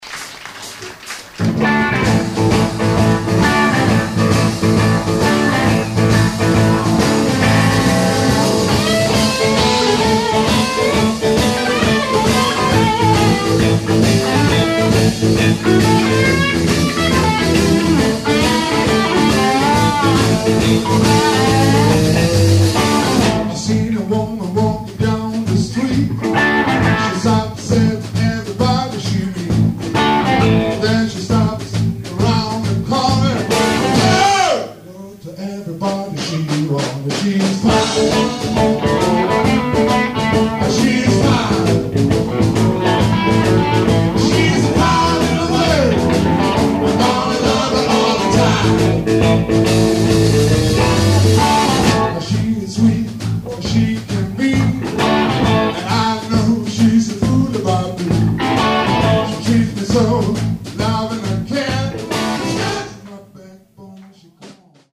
LIVE, TRE BACKAR,
STOCKHOLM 1984